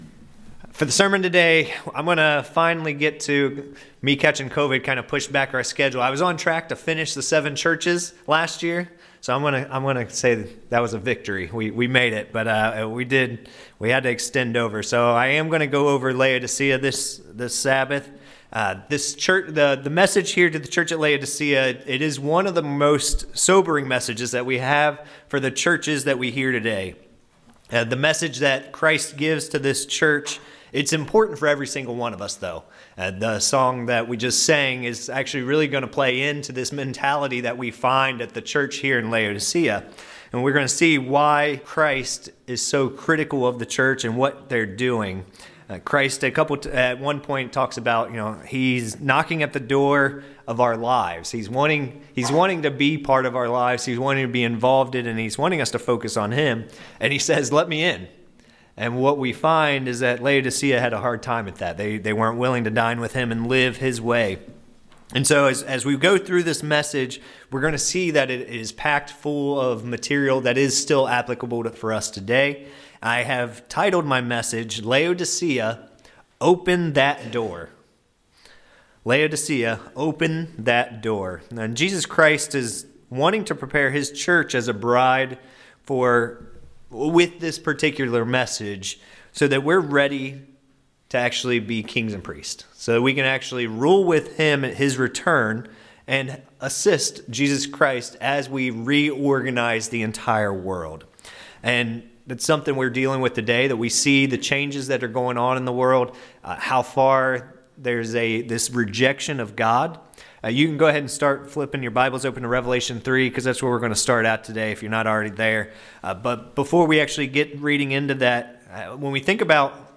This sermon takes a close look at the message to the Laodicia church. They all faced something that was turning them away from God.
Given in Ft. Wayne, IN